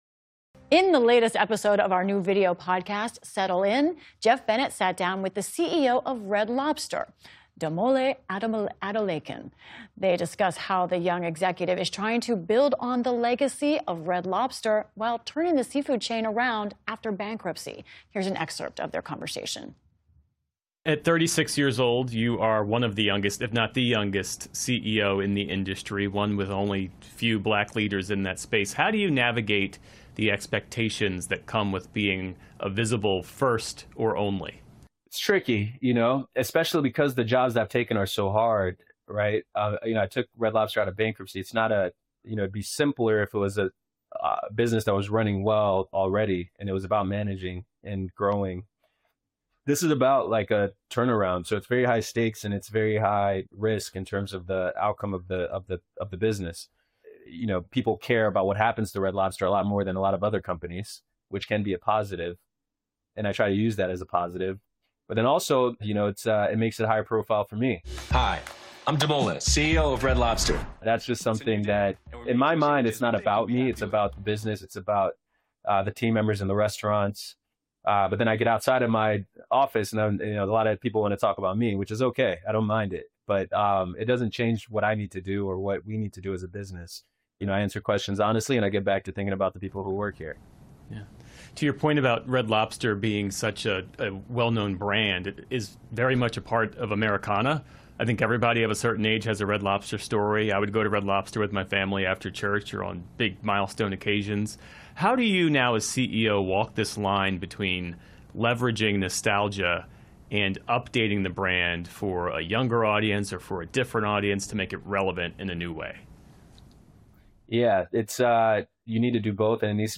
In the latest episode of our video podcast, "Settle In," Geoff Bennett sat down with the Red Lobster CEO Damola Adamolekun. They discuss how the young executive is trying to build on the legacy of Red Lobster while turning the seafood chain around after bankruptcy.